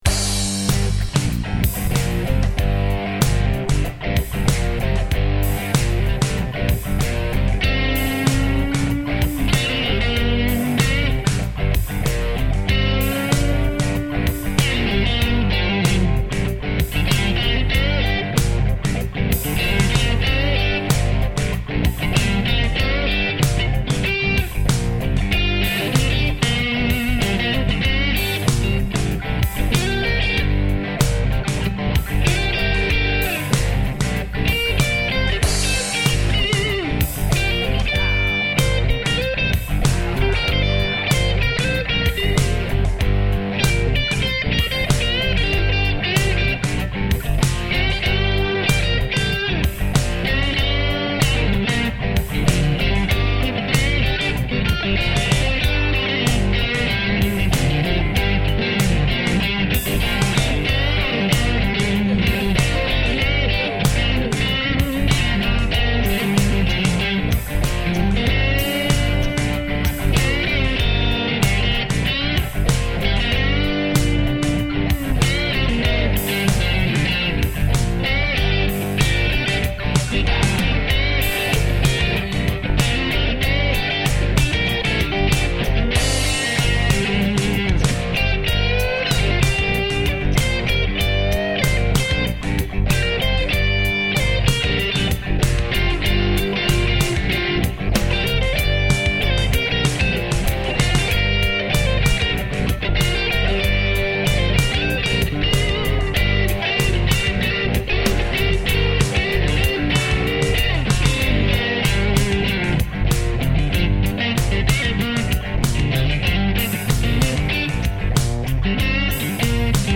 Und so klingt sie, habe ich vor Jahren einmal aufgenommen. Die Rhythmusgitarre ist eine Fender Tele (Mexico): g-blues.mp3